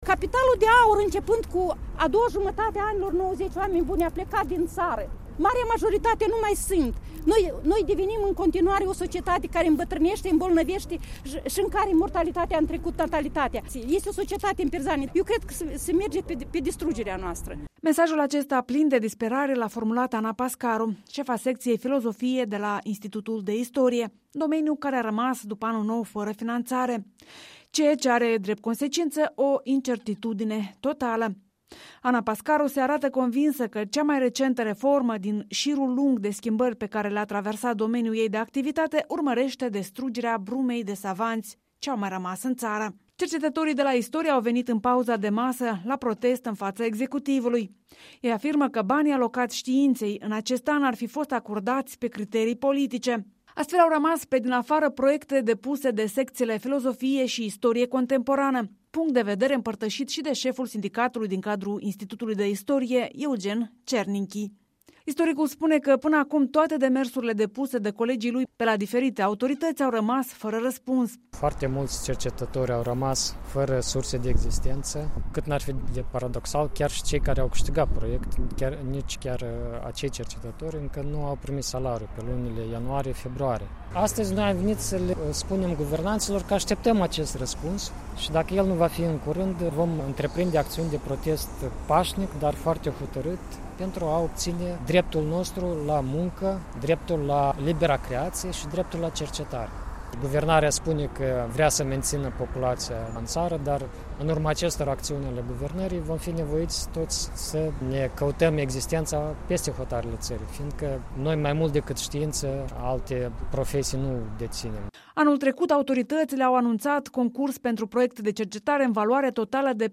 Protestul din fața clădirii Guvernului